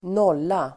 Uttal: [²n'ål:a]